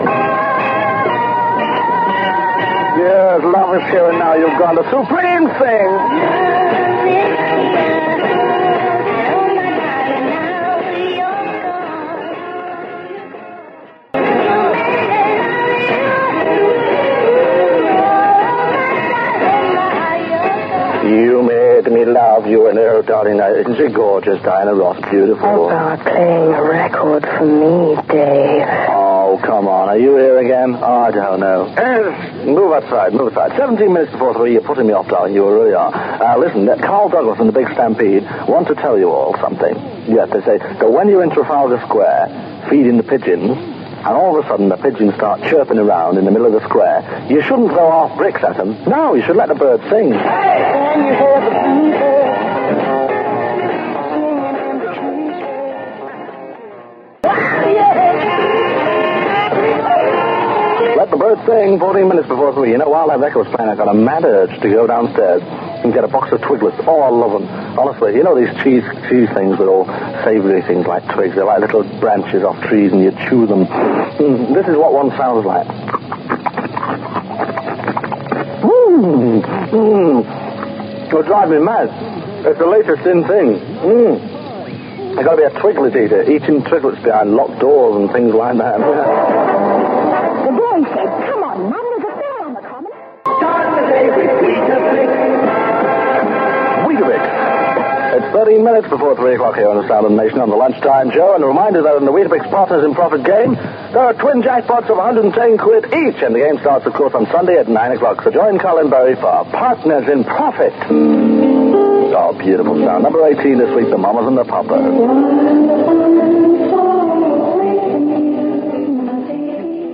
click to hear audio DLT on his regular lunchtime show on Radio Caroline South from 25th May 1967, his 22nd birthday.